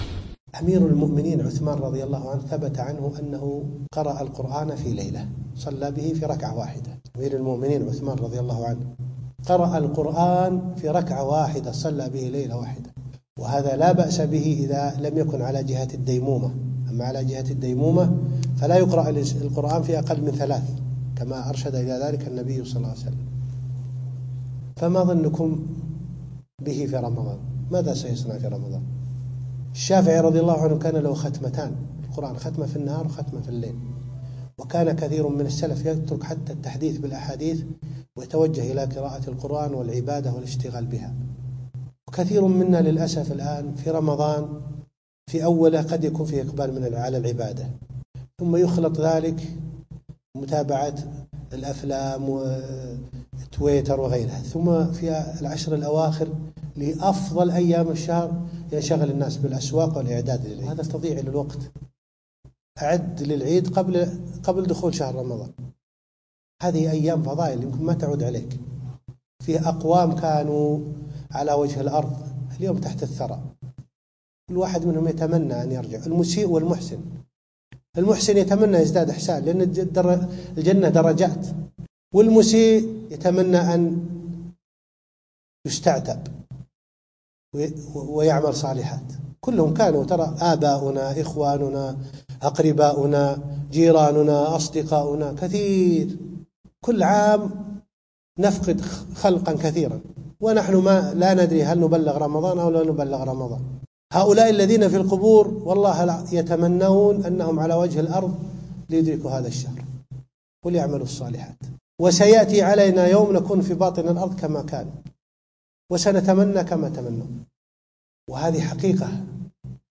موعظة كل ما ذهب لايمكن أن يعود